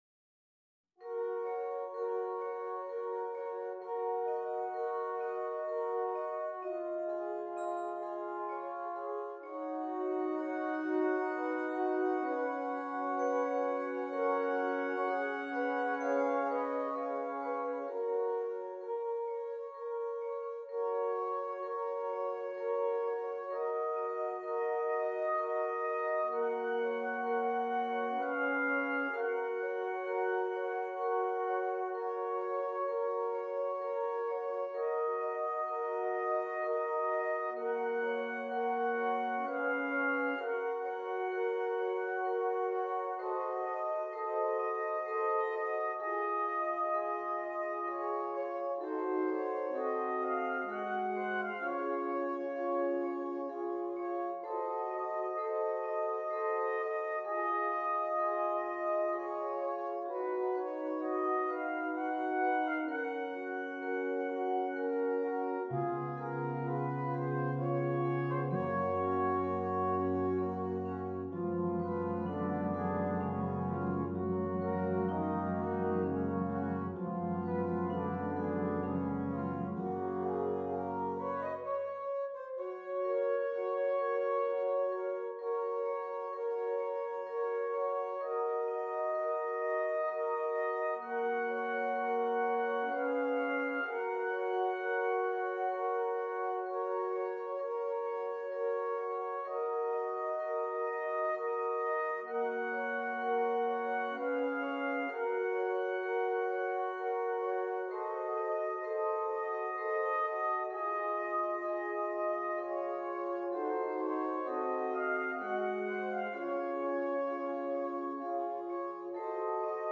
zartes und sanftes Liebeslied
Besetzung: Soprano Cornet Solo & Ten Piece